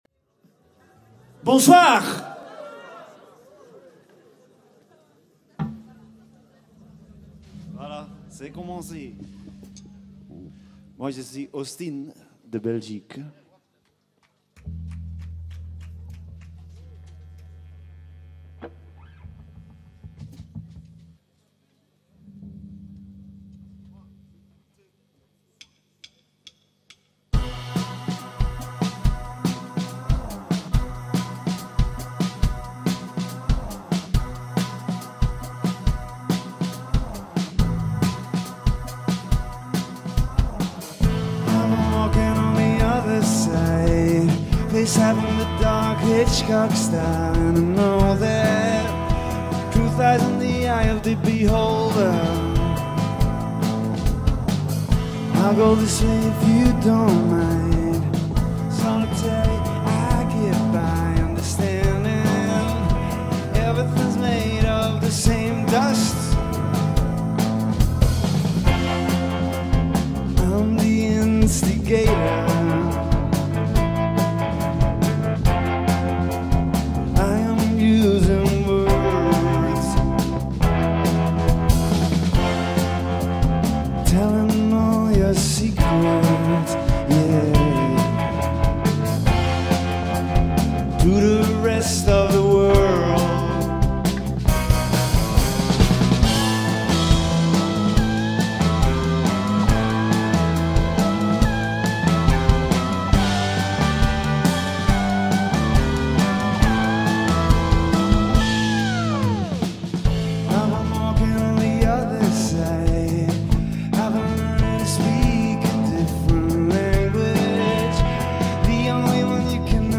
Belgian Indie/Psych/Alt band